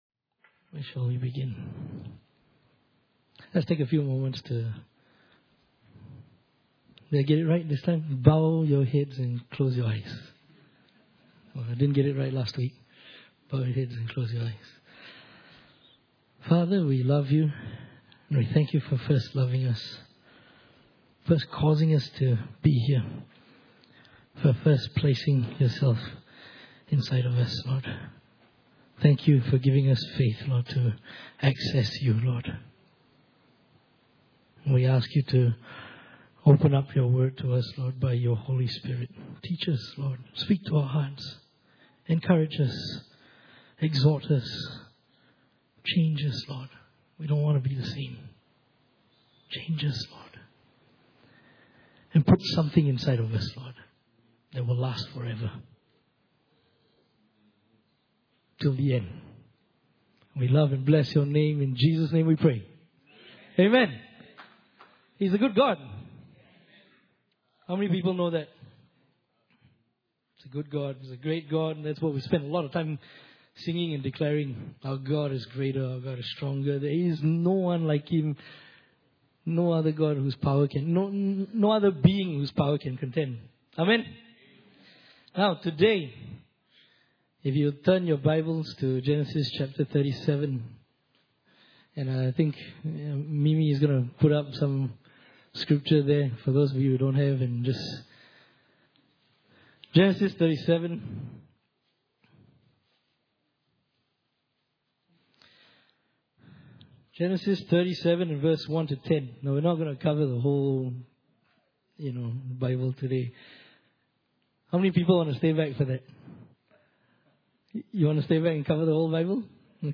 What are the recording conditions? Inhouse Service Type: Sunday Morning « Tracking Jesus’ Death